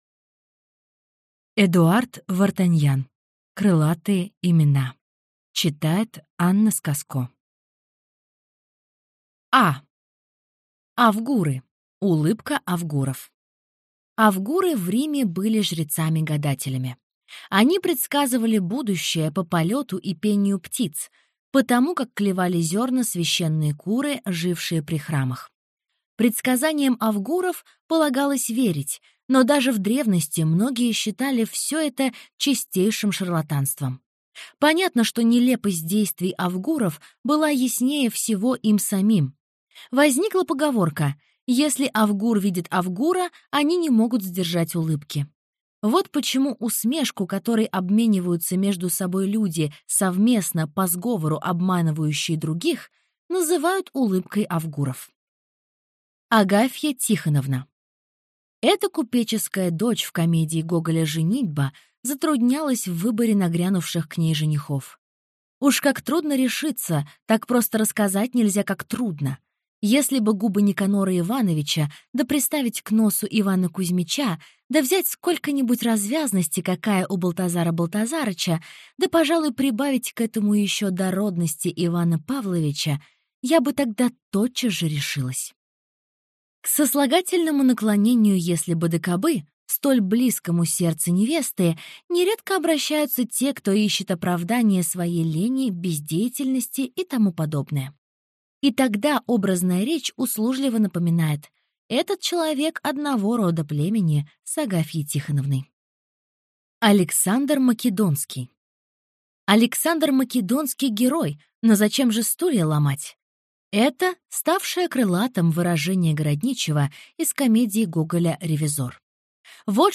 Аудиокнига Крылатые имена | Библиотека аудиокниг